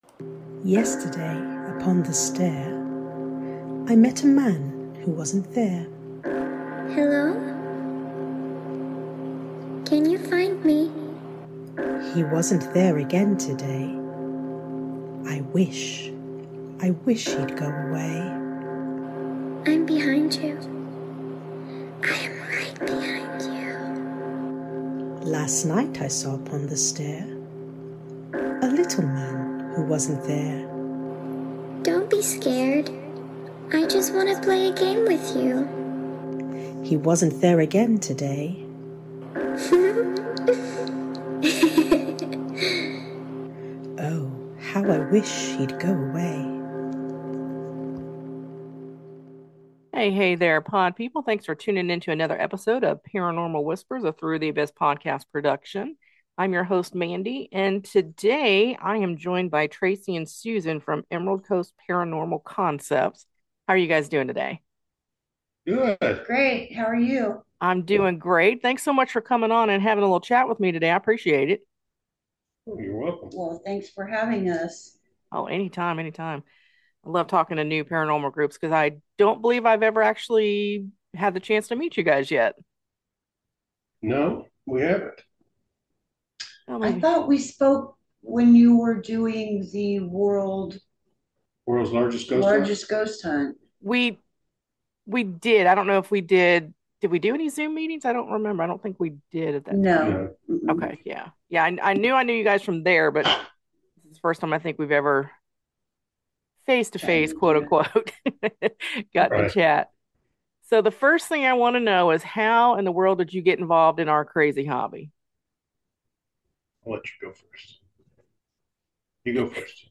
They share some cool stories and some amazing EVPs.